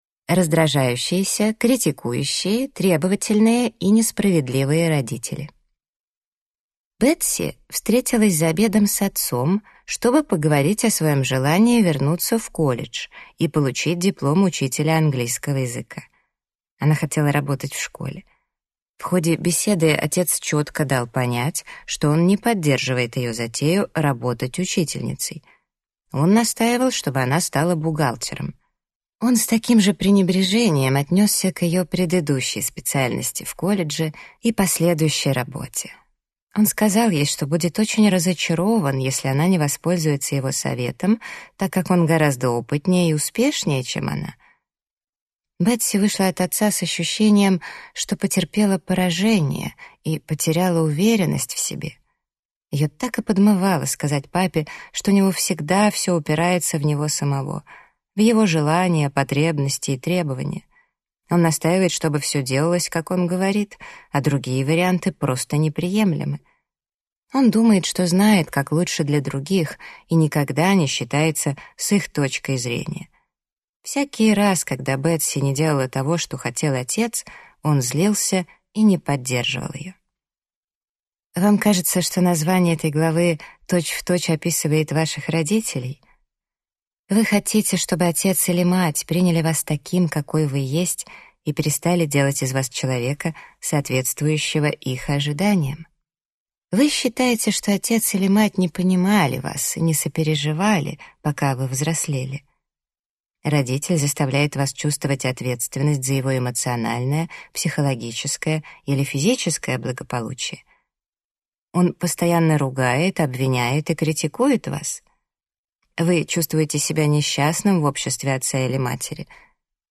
Аудиокнига Они не изменятся. Как взрослым детям преодолеть травмы и освободиться от токсичного влияния родителей | Библиотека аудиокниг